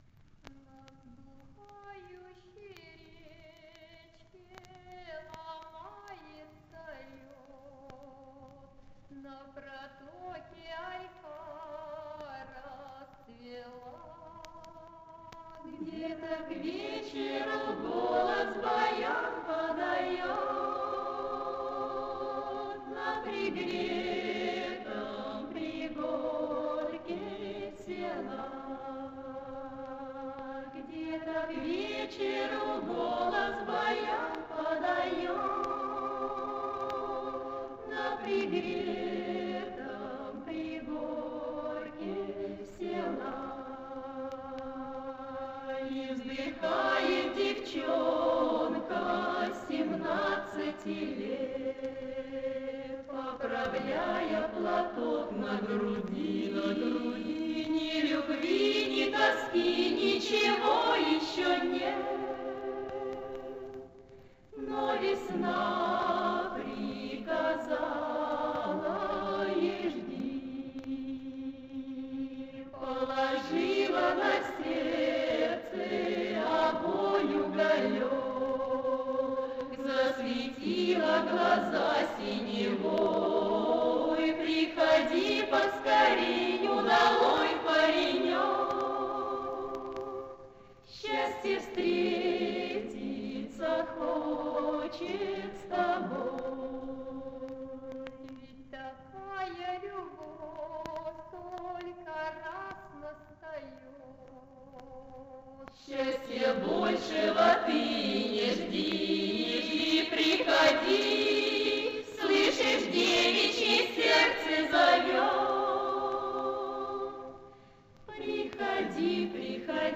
исполнение, возможно, женского вокального ансамбля.